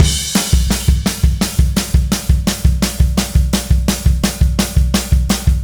Indie Pop Beat 01 Crash.wav